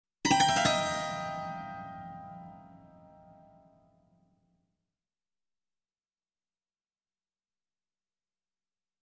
Мистика звуки скачать, слушать онлайн ✔в хорошем качестве